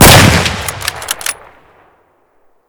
shoot_1.ogg